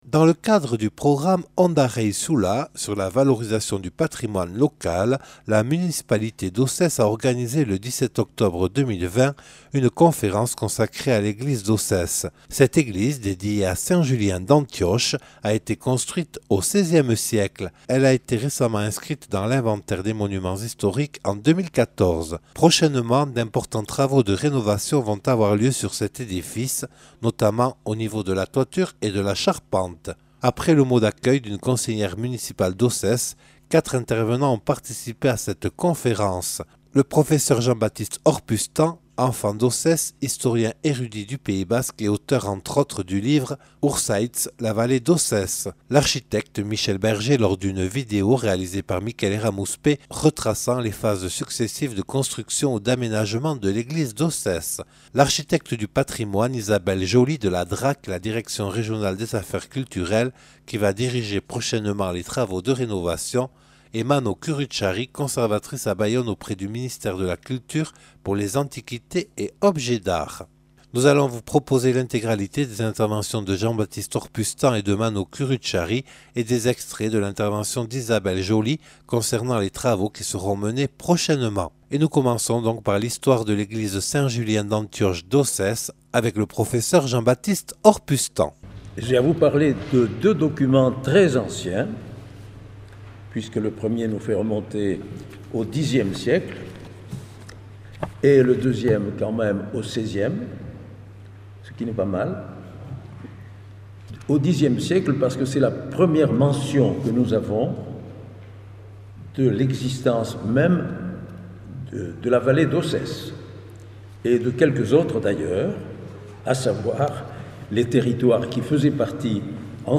Dans le cadre du programme « Ondareizula » sur la valorisation du patrimoine local, la municipalité d’Ossès a organisé le 17 octobre 2020 une conférence consacrée à l’église d’Ossès.